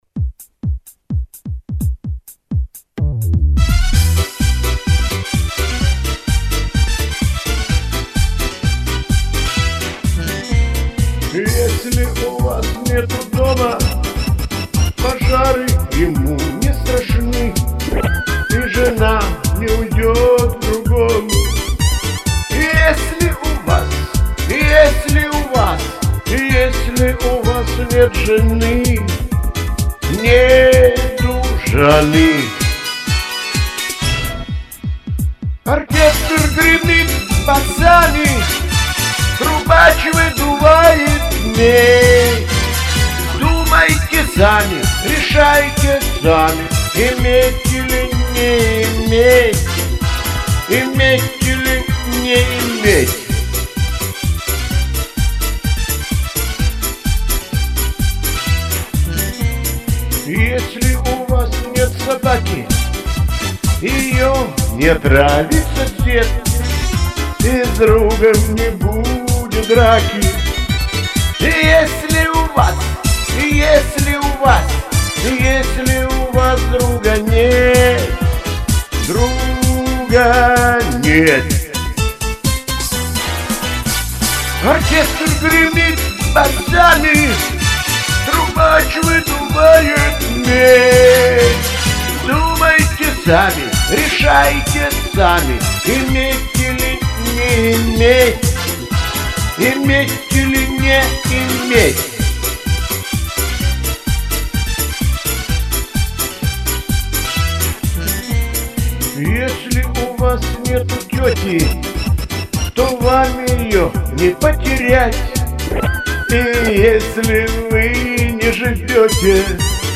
и исполнение более под стиль блатного шансона получилось